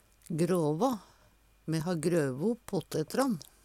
DIALEKTORD PÅ NORMERT NORSK gråvå grave Infinitiv Presens Preteritum Perfektum gråvå grev grov grøve Eksempel på bruk Me ha grøve opp pottetran. Hør på dette ordet Ordklasse: Verb Kategori: Jordbruk og seterbruk Attende til søk